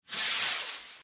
shoot_alt.ogg